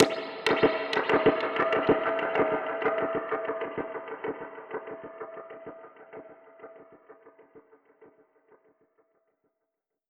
Index of /musicradar/dub-percussion-samples/95bpm
DPFX_PercHit_D_95-07.wav